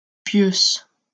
Pieusse (French pronunciation: [pjøs]